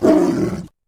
CosmicRageSounds / wav / general / combat / creatures / tiger / she / attack2.wav